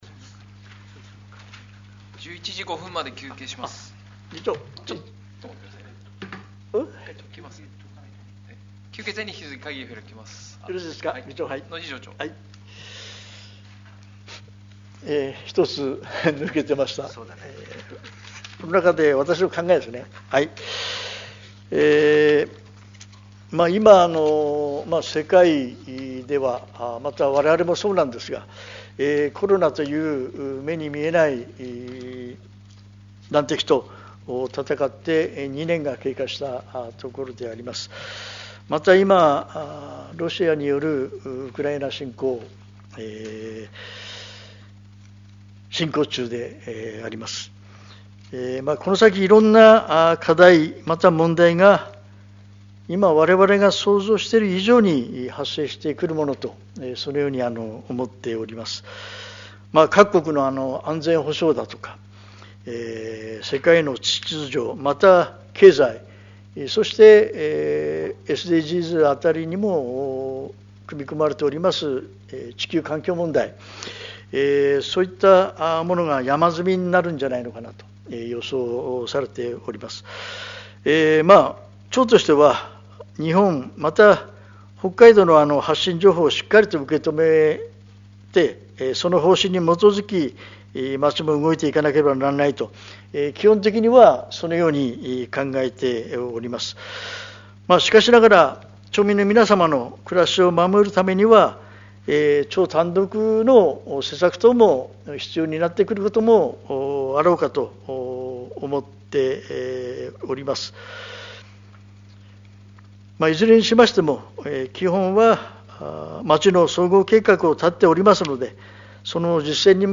12月定例会